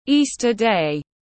Ngày lễ phục sinh tiếng anh gọi là Easter day, phiên âm tiếng anh đọc là /ˌiː.stə ˈdeɪ/
Easter day /ˌiː.stə ˈdeɪ/